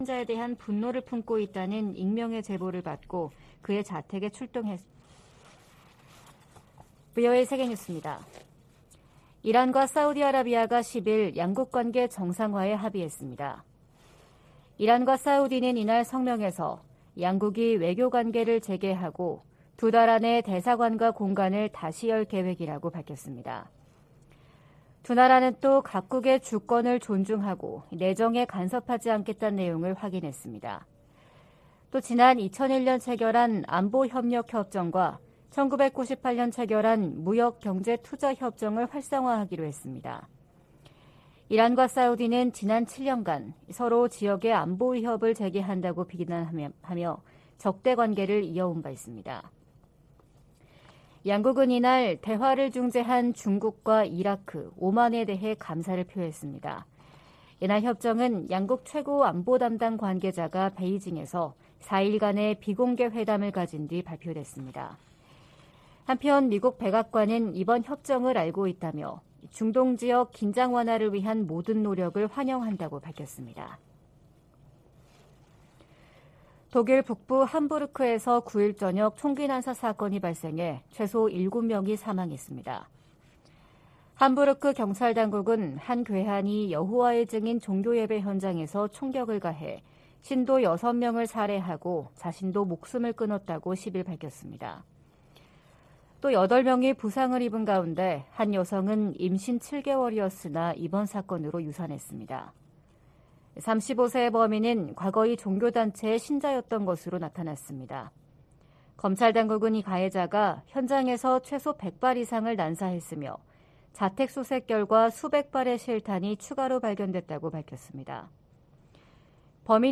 VOA 한국어 '출발 뉴스 쇼', 2023년 3월 11일 방송입니다. 북한이 9일 신형 전술유도무기로 추정되는 탄도미사일을 최소 6발 서해로 발사했습니다. 미 국무부는 북한의 미사일 발사를 규탄하며, 대화에 열려 있지만 접근법을 바꾸지 않을 경우 더 큰 대가를 치르게 될 것이라고 경고했습니다. 미국 전략사령관이 의회 청문회에서 북한의 신형 대륙간탄도미사일로 안보 위협이 높아지고 있다고 말했습니다.